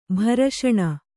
♪ bharaṣaṇa